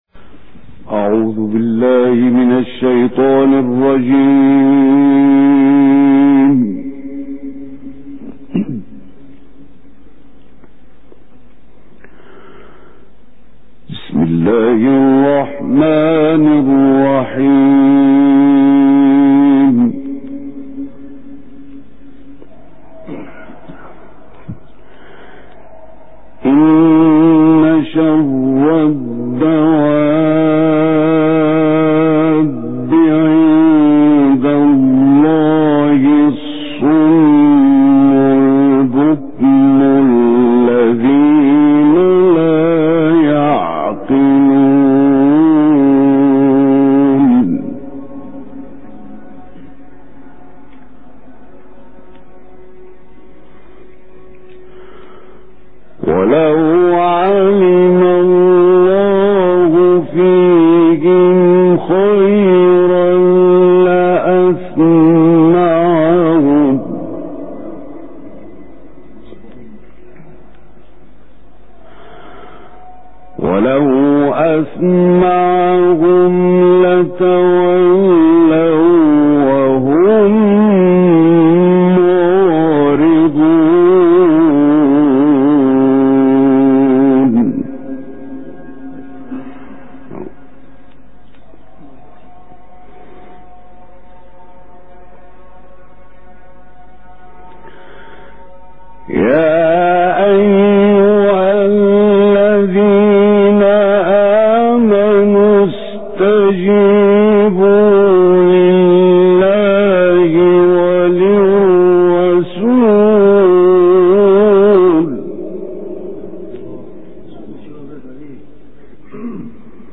May God make us meet your good expectations - El Forqaan For Recitations and Quran Science